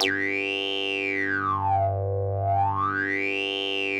G2_wasp_lead_1.wav